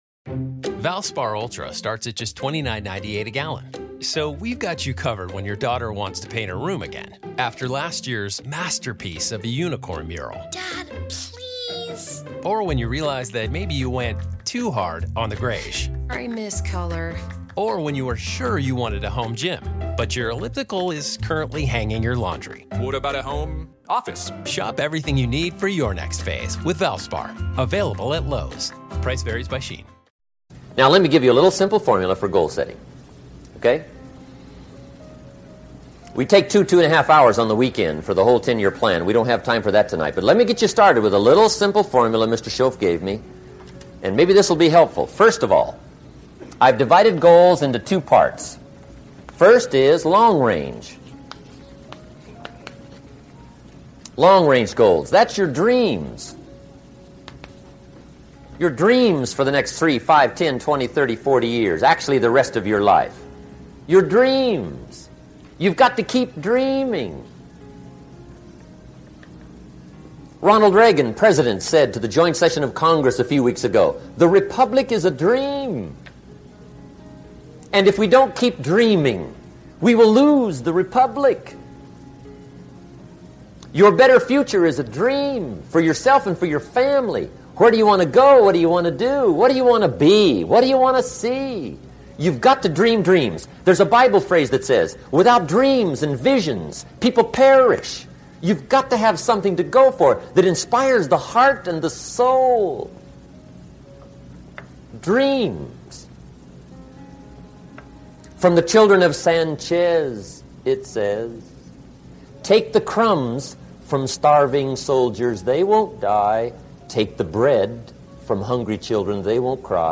Speaker: Jim Rohn